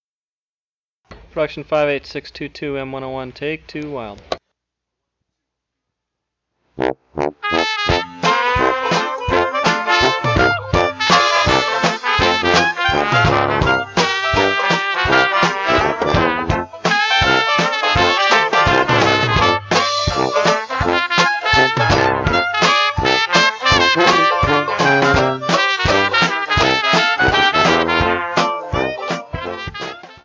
UPC: Soundtrack